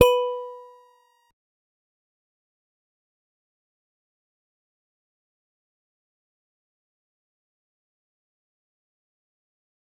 G_Musicbox-B4-mf.wav